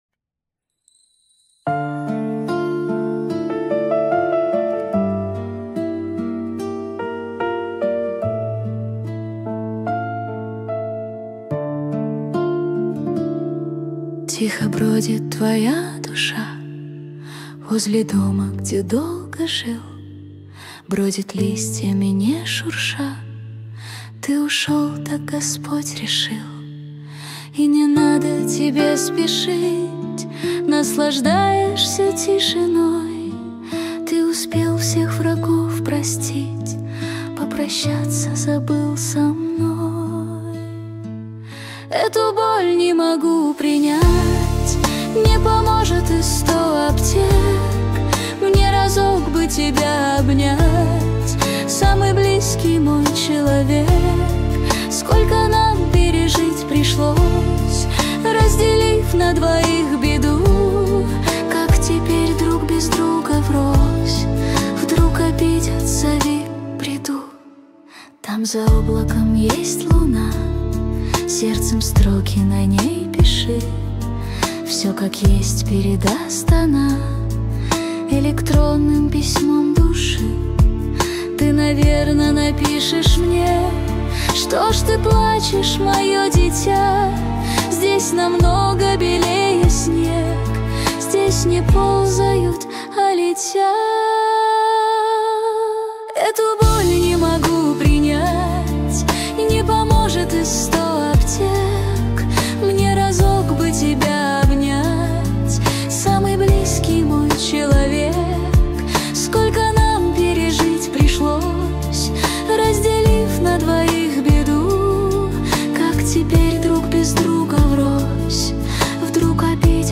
13 декабрь 2025 Русская AI музыка 76 прослушиваний